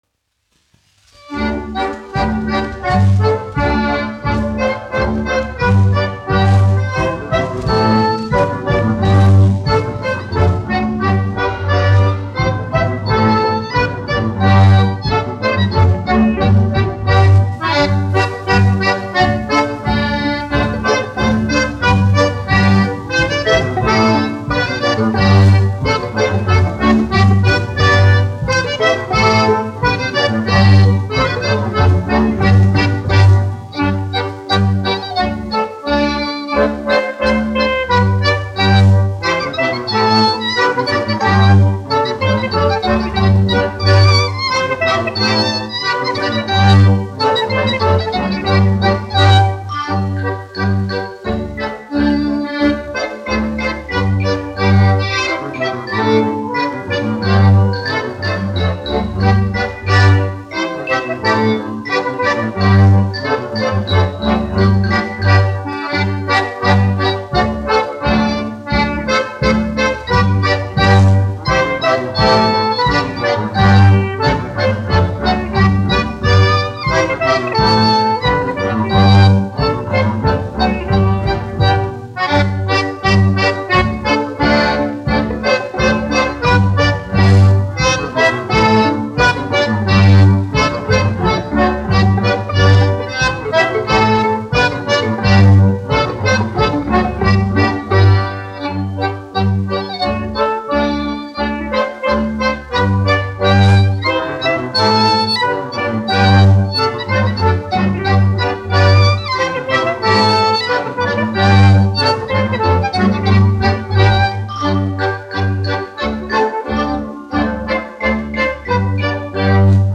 1 skpl. : analogs, 78 apgr/min, mono ; 25 cm
Latviešu tautas dejas
Skaņuplate